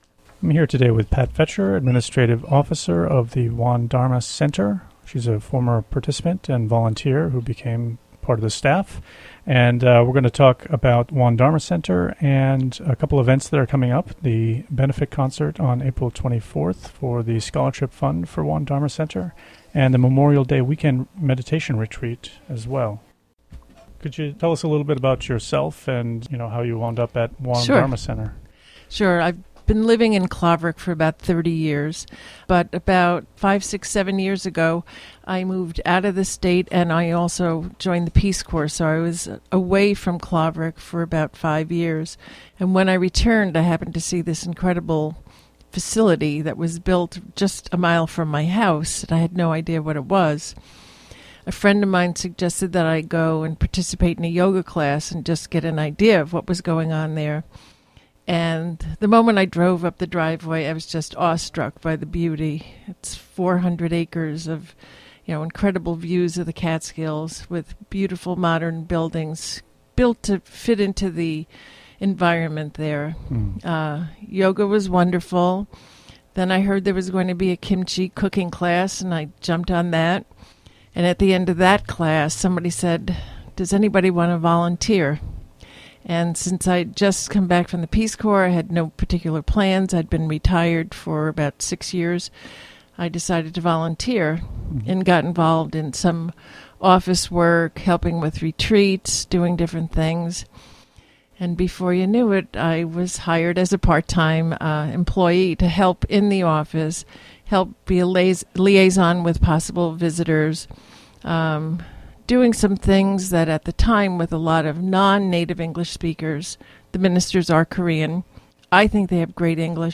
WGXC Morning Show